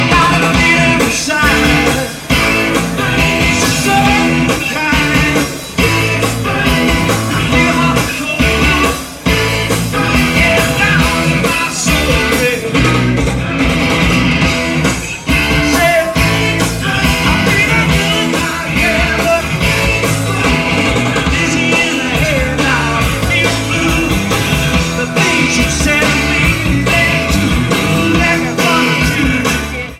Comments: Very good mono soundboard recording*.